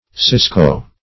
Cisco \Cis"co\, n. (Zool.)